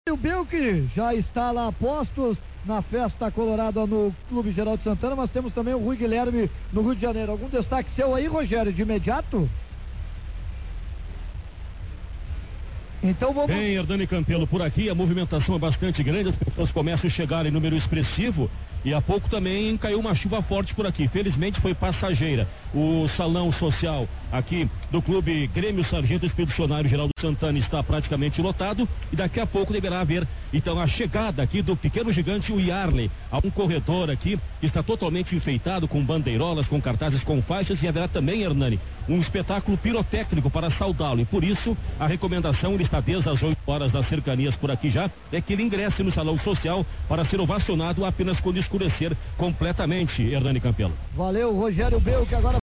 Transmissão Rádio Guaíba Homenagem Iarley – parte 02